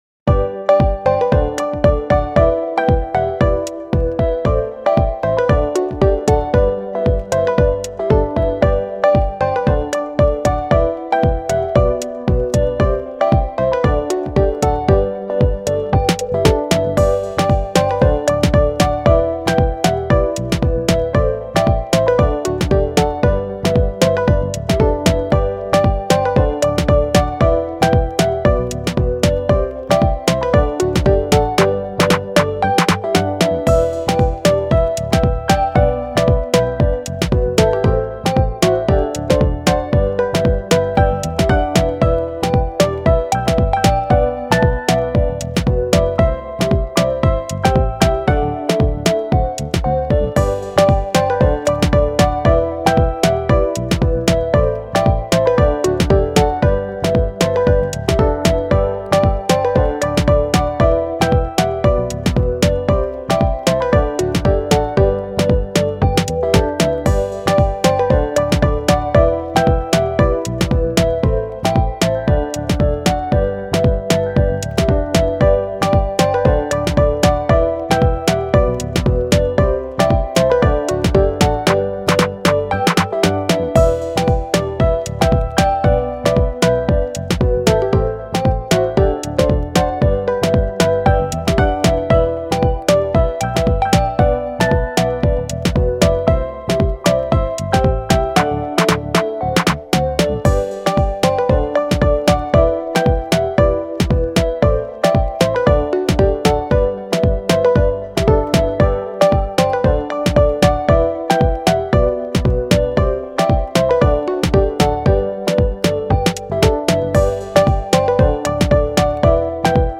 明るい・ポップ